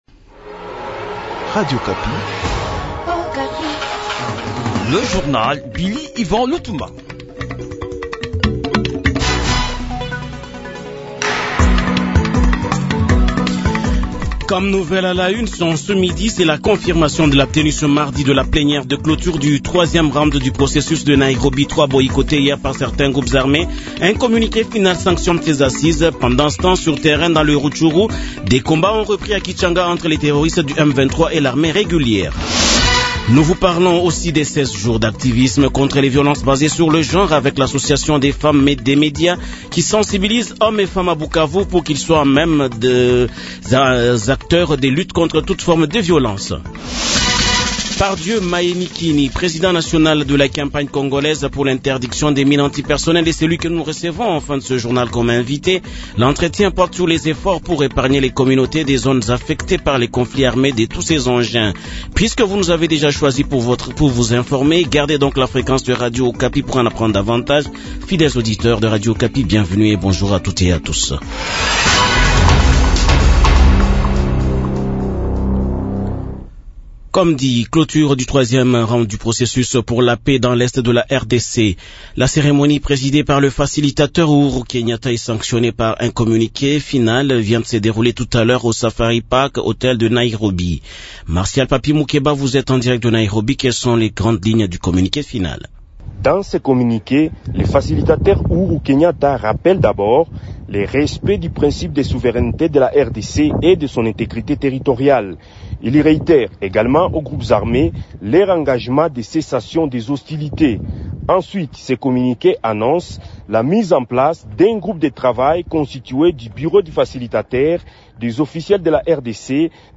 Journal Midi